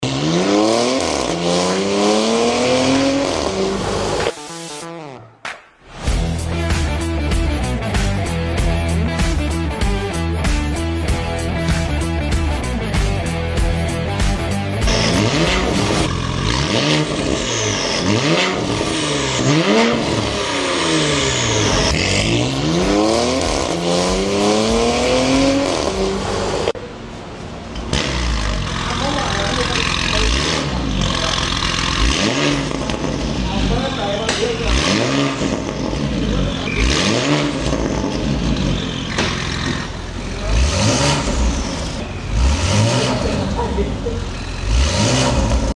Porsche Panamera 971 Titanium Catabck